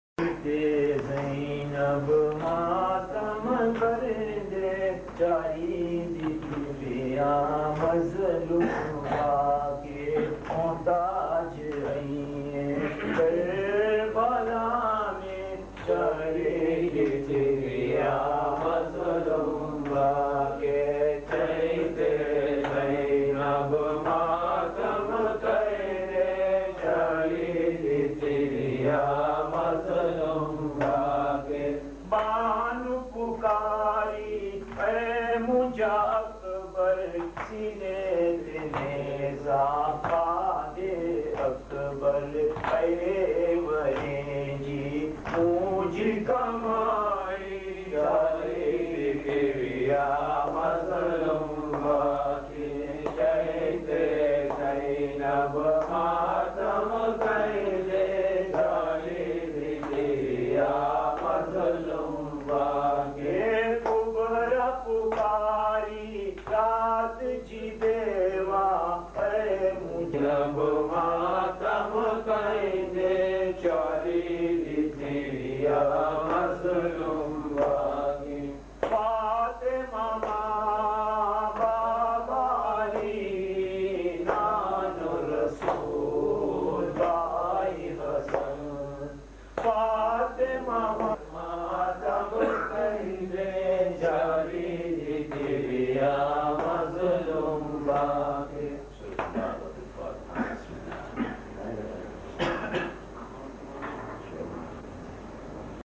Non Saff Calssic Marsia / Nawha